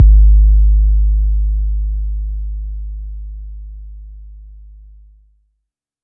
Blow 808.aif